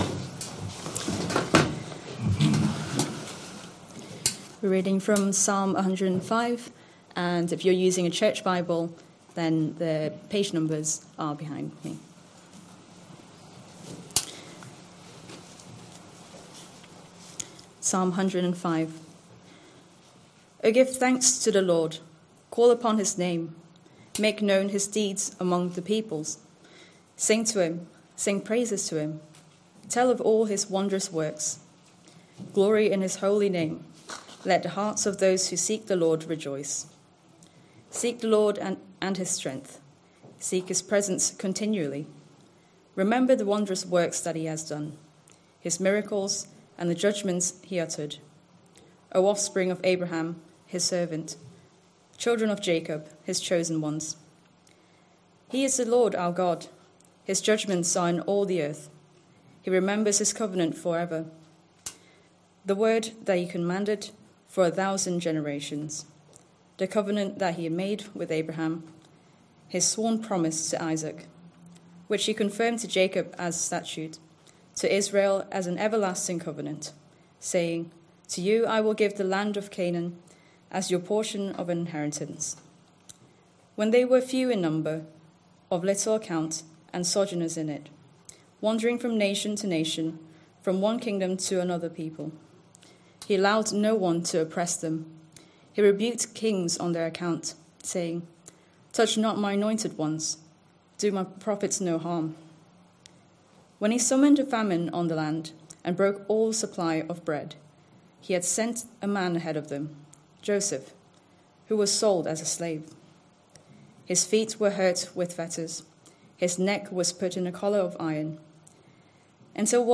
Christ Church Sermon Archive
Sunday PM Service Sunday 25th January 2026 Speaker